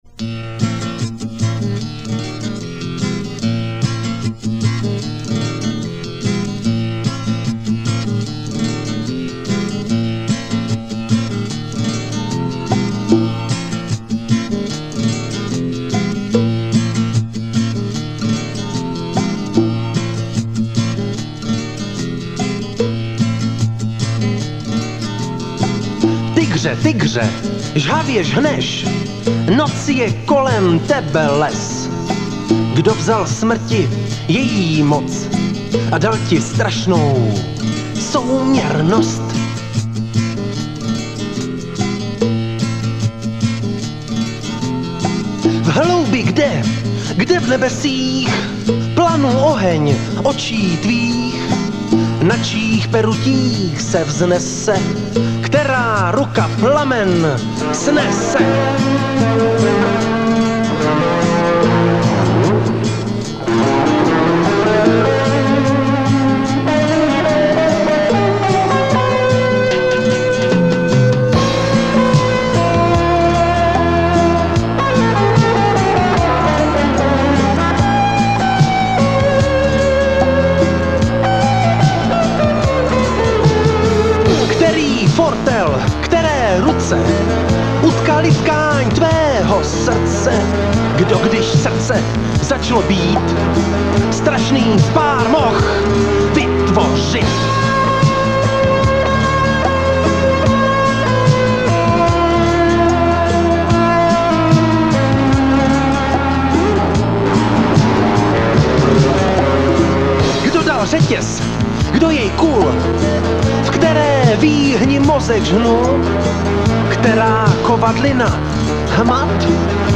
art-rock/mininal/alternatíva z Valašska.